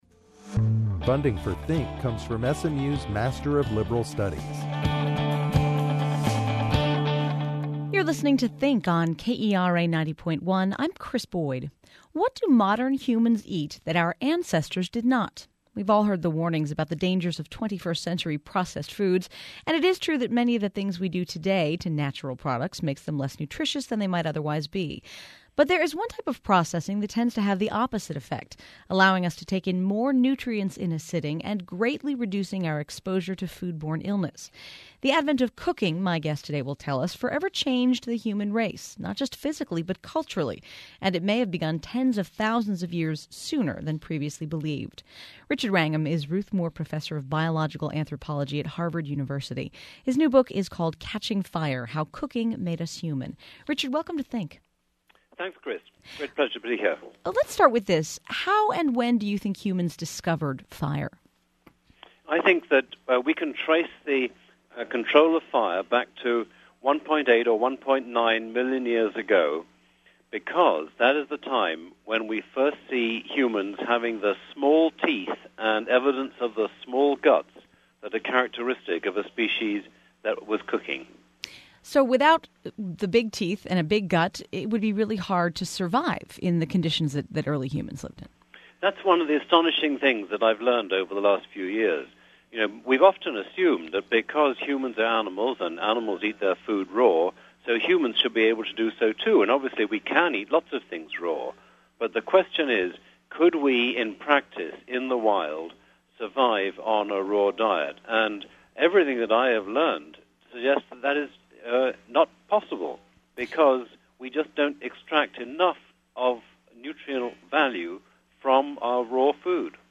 A podcast with an interview with the author is available as an MP3 download. It’s about 45 minutes and gives a decent overview of the book.